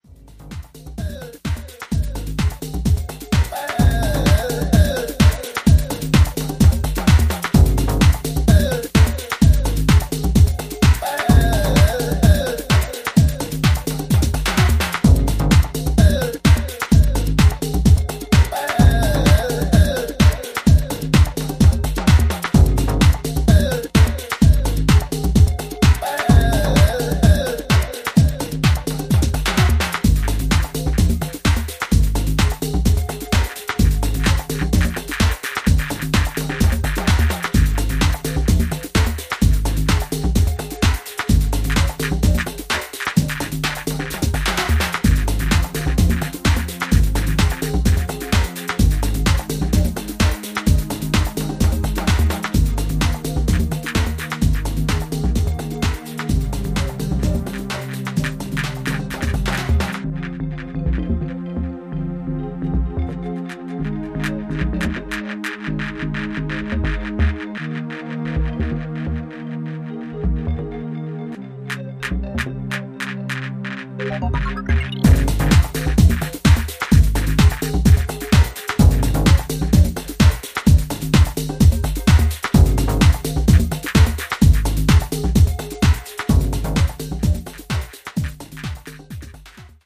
トライバル・グルーヴに馴染んだサイファイなシンセリフが強烈な